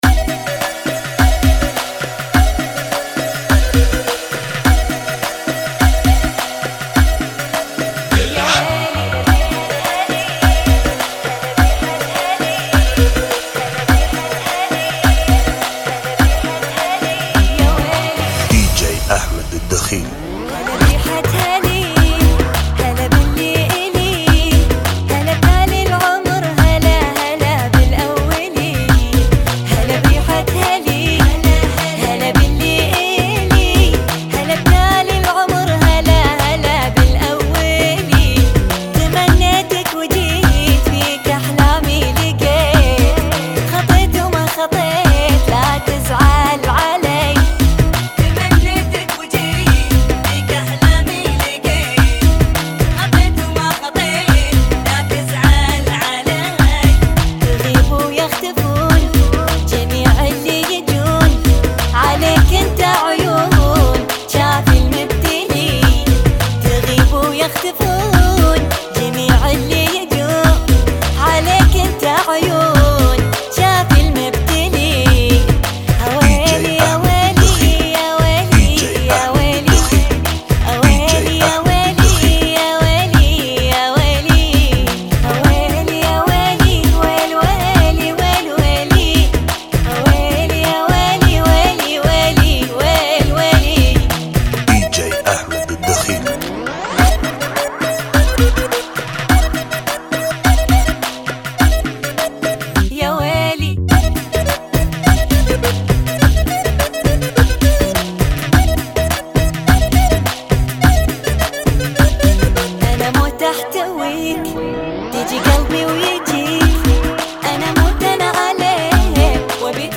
Funky Remix